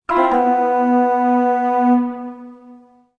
Descarga de Sonidos mp3 Gratis: separador musical.